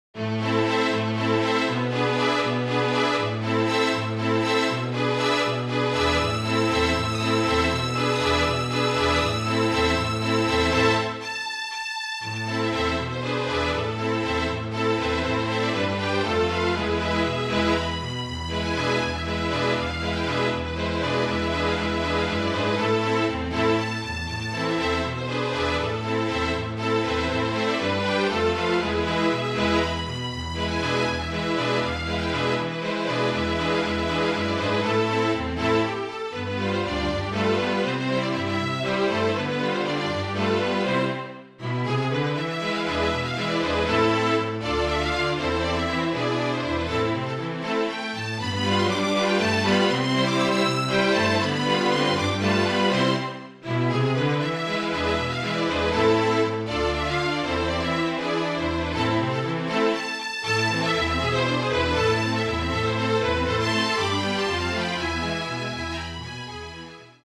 LATIN SONGS
(Flute, Violin, Viola and Cello)
or STRING QUARTET
MIDI